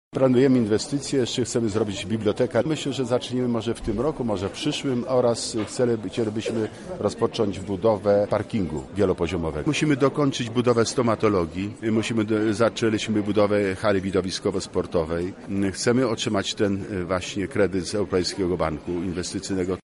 Na Uniwersytecie Medycznym miała miejsce uroczysta inauguracja roku